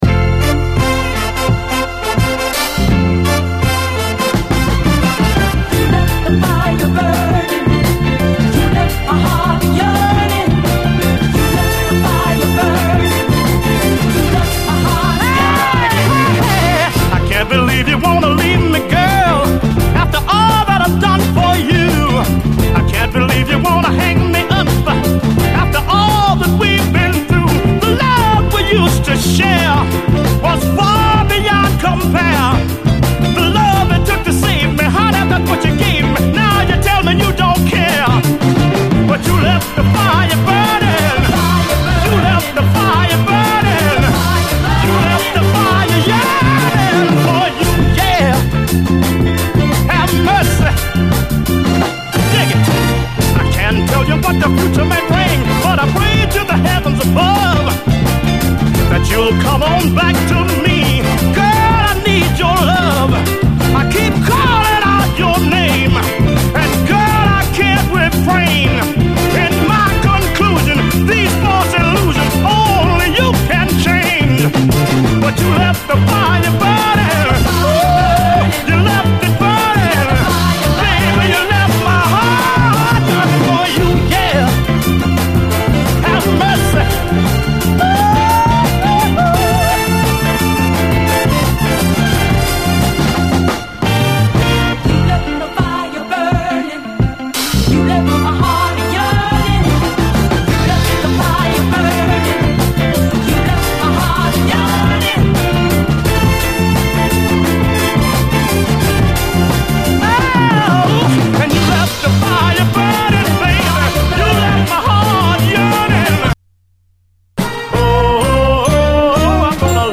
SOUL, 70's～ SOUL
ポジティヴティーが眩しい溌剌サンシャイン・ソウル